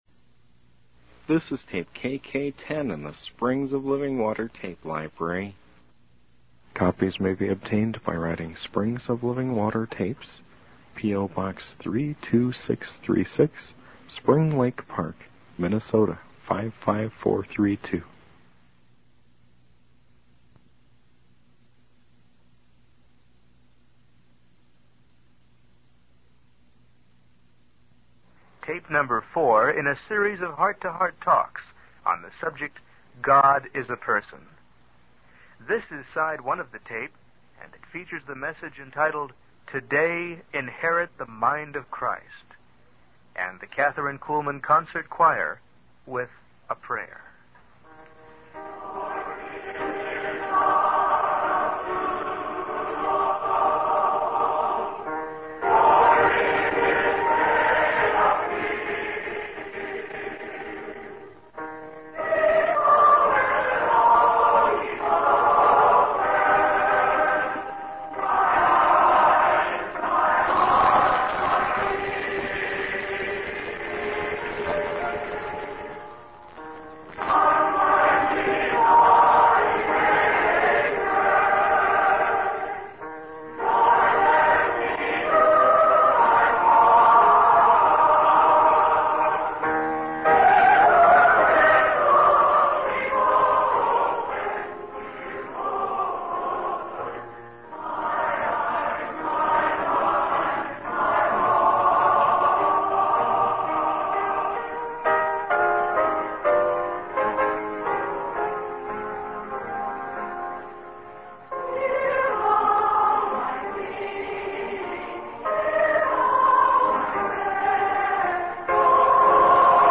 In this sermon, the speaker emphasizes the importance of understanding that God is a person.